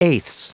Prononciation du mot eighths en anglais (fichier audio)
Prononciation du mot : eighths